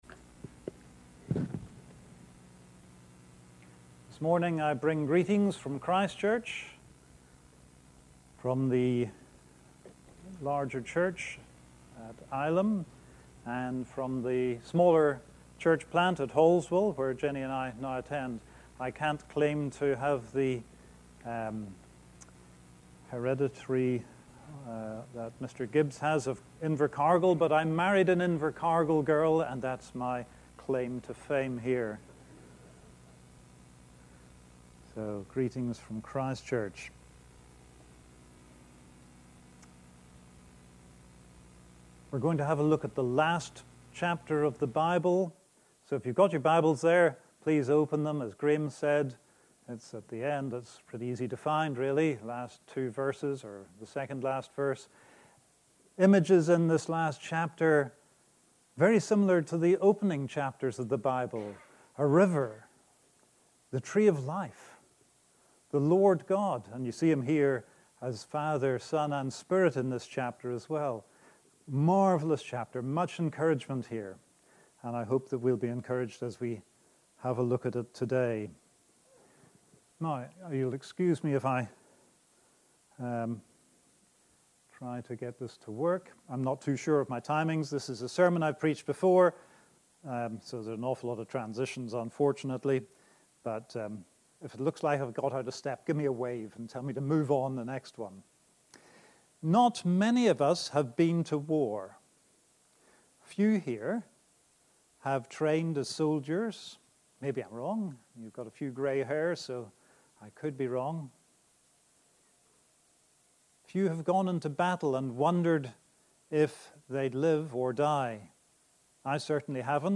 I have printed out some brief notes detailing some of the various options put forward, so that the sermon is not derailed by this kind of discussion.